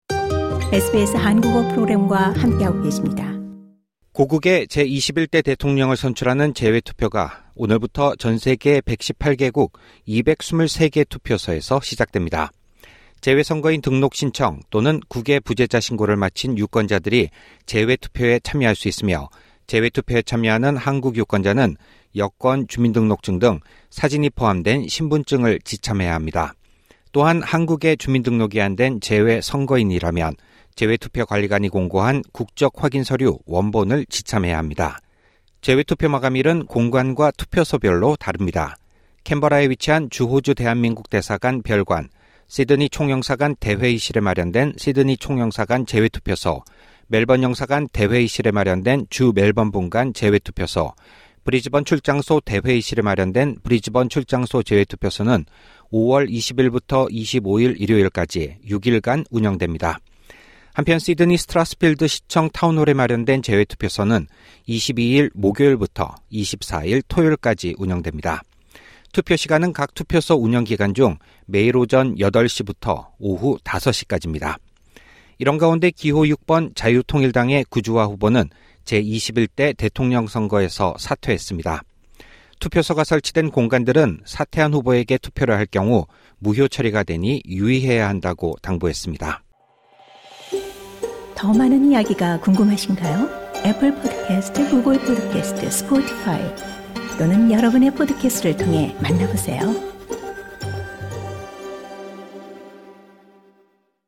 LISTEN TO 5월 20일 한국 제21대 대통령 재외 선거 투표일, 알아야 할 사항 SBS Korean 02:03 Korean 상단의 오디오를 재생하시면 뉴스를 들으실 수 있습니다.